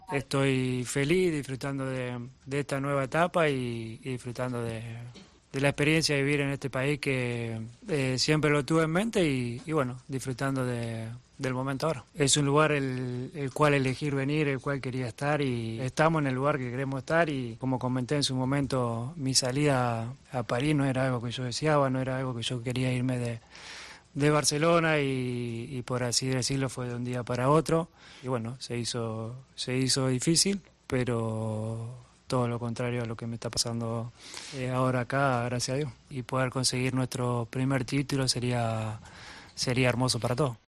AUDIO: El futbolista del Inter de Miami habló de su llegada a la MLS y la diferencia con su marcha del Barcelona al PSG.